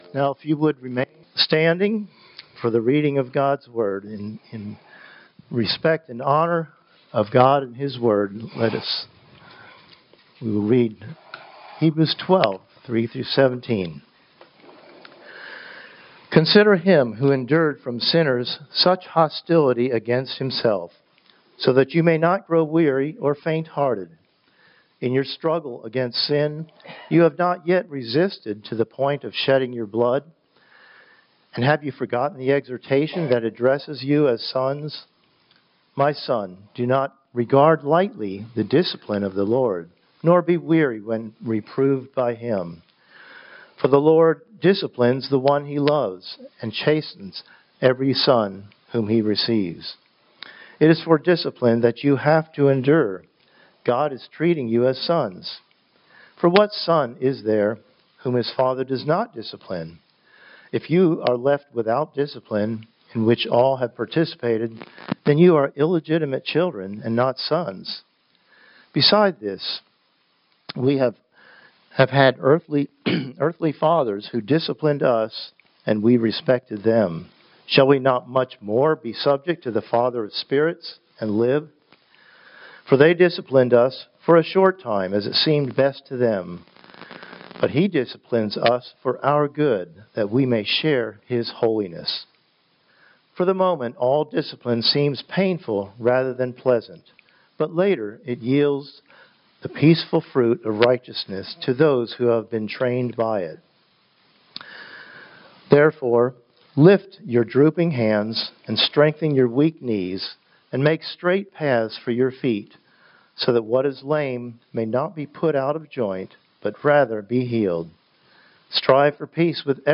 Sermons | Oak Community Church (PCA)